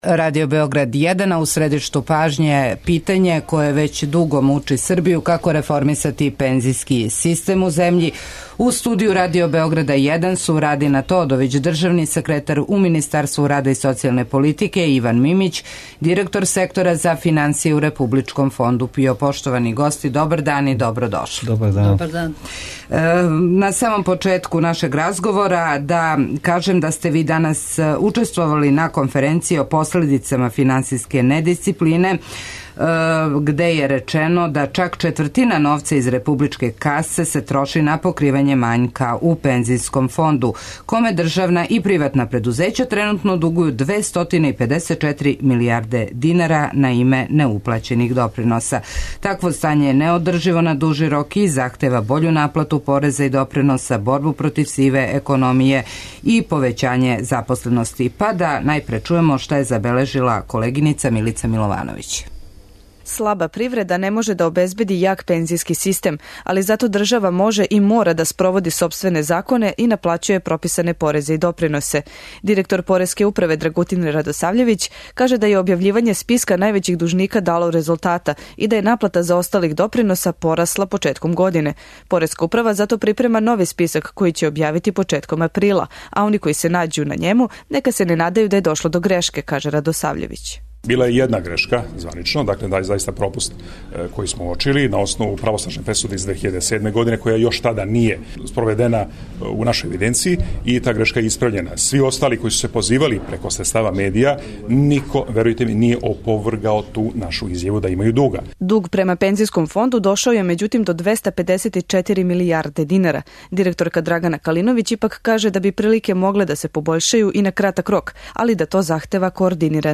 Гости емисије су - Радина Тодовић, државни секретар у Министарству рада и социјалне политике